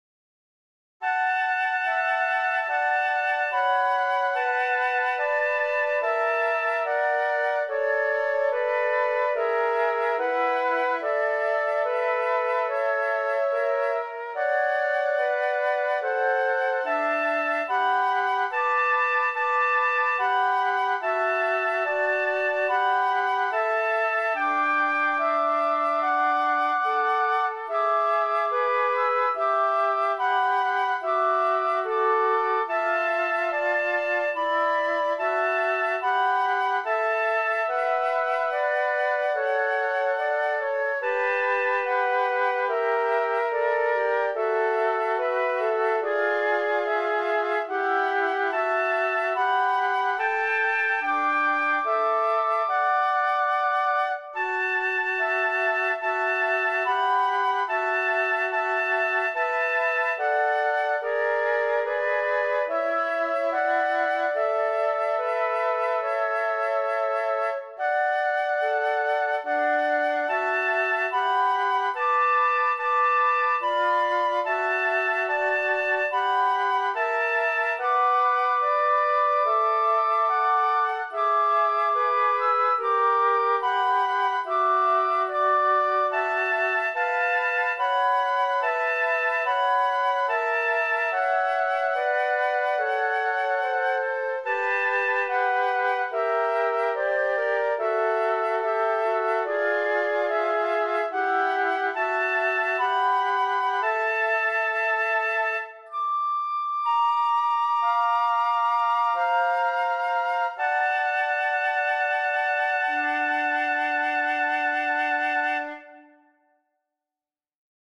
（Ａ）と聴いた感じあまり変りませんが、どちらかと言えば（Ｂ）は聴いてもらう用でしょうか。
ハーモニーとか微妙なので、どちらかと言えば聴いてもらう用のアレンジです。